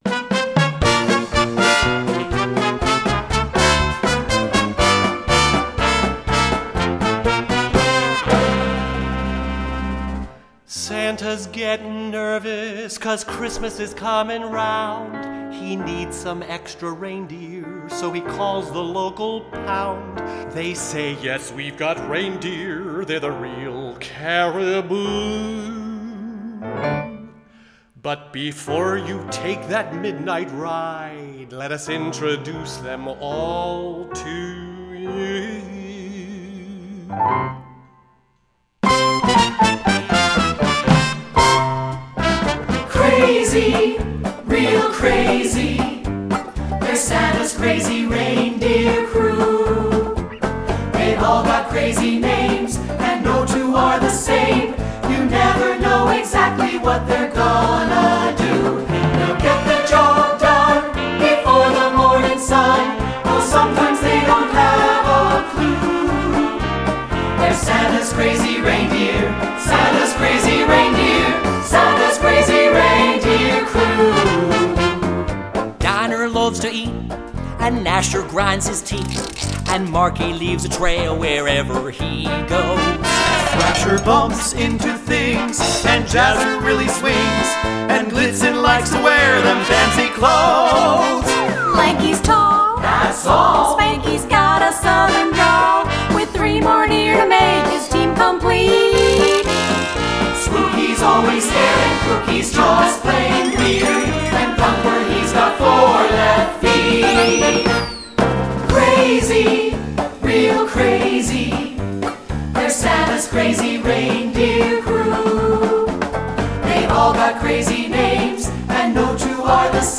secular choral